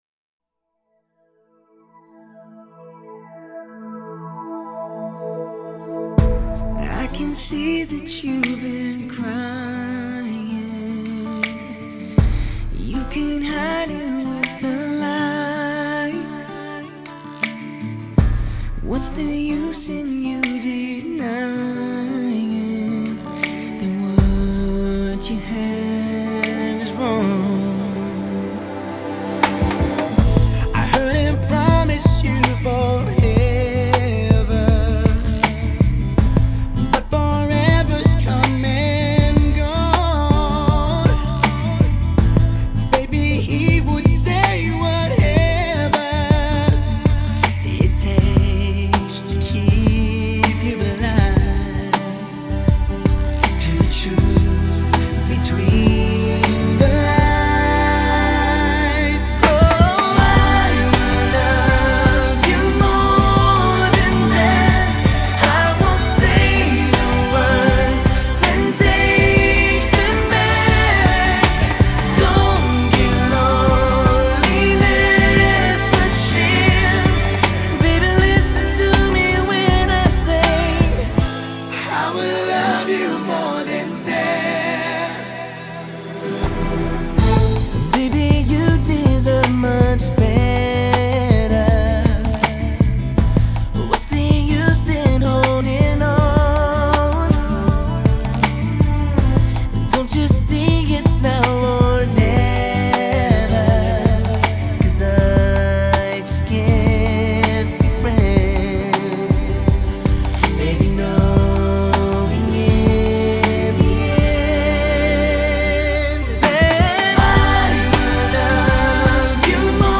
slower melodies